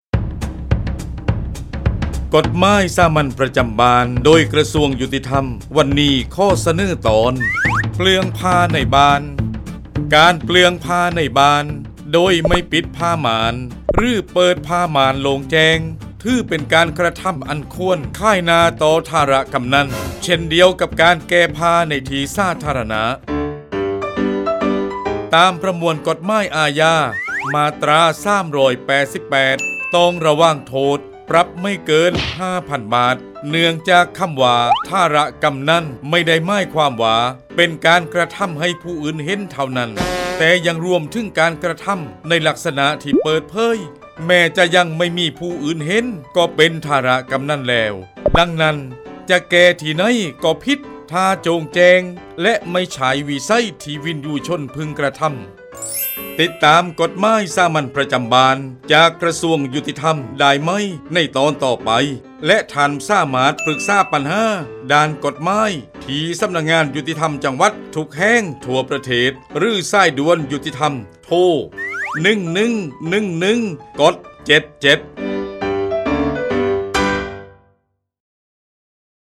กฎหมายสามัญประจำบ้าน ฉบับภาษาท้องถิ่น ภาคใต้ ตอนเปลื้องผ้าในบ้าน
ลักษณะของสื่อ :   บรรยาย, คลิปเสียง